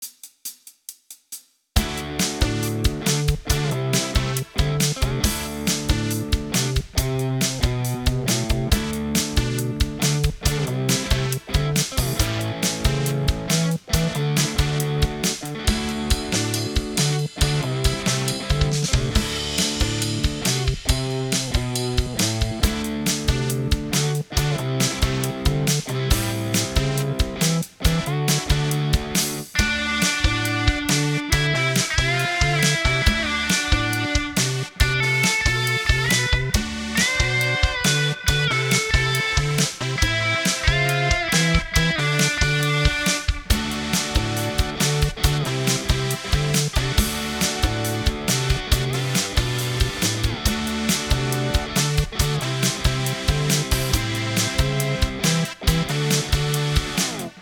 Released: ToneBoosters Flowtones - virtual analog synthesizer - Page 5
Just for the halibut, I recorded a brief song fragment using the slightly stabby horns in Flowtones.